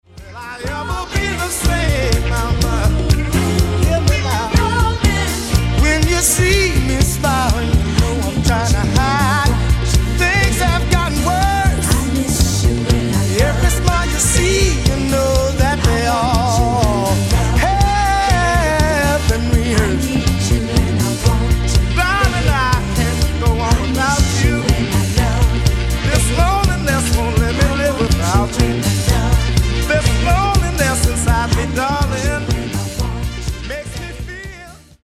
Genere:   Disco | Funky | Soul